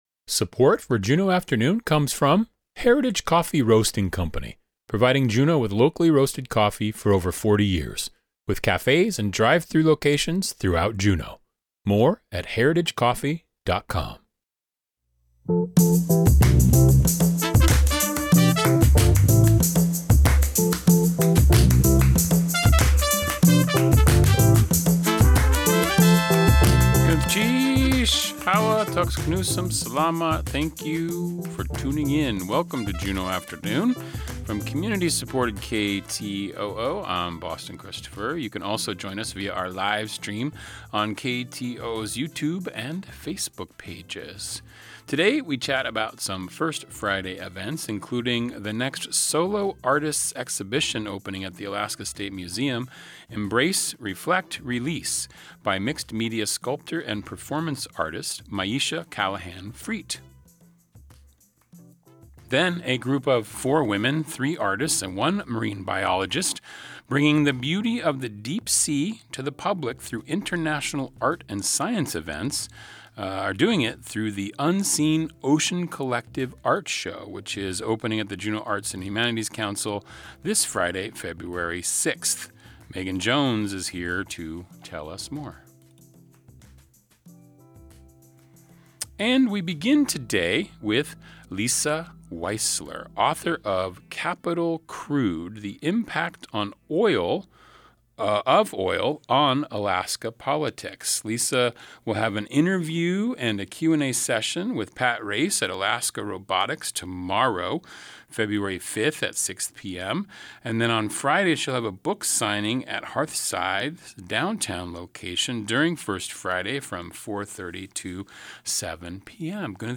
And thank you to KTOO Public Radio and Juneau Afternoon for giving me an opportunity to talk about my book. It was an interesting and lively conversation that can be heard at KTOO Juneau Afternoon